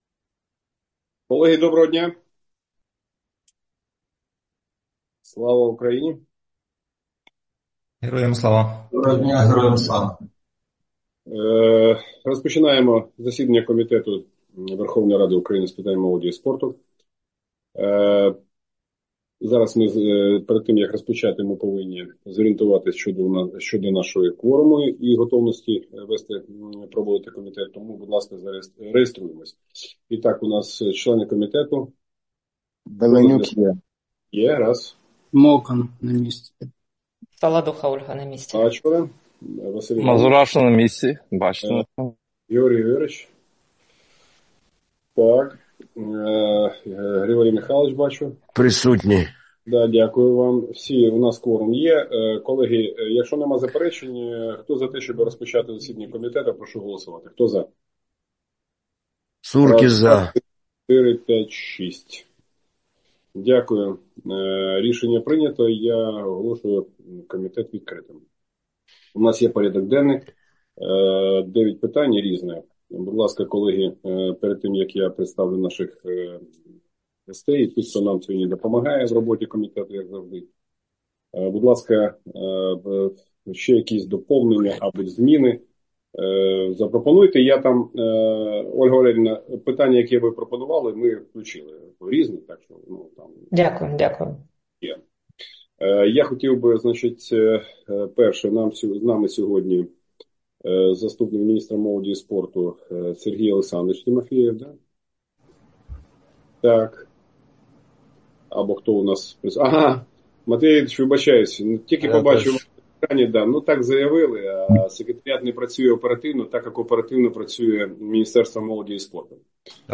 Аудіозаписи засідання Комітету у листопаді 2025 року